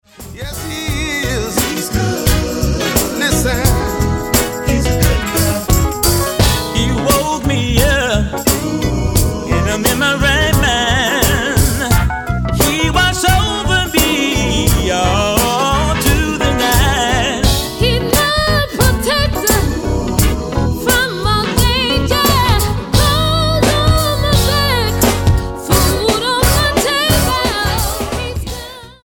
Californian gospel duo
Style: Gospel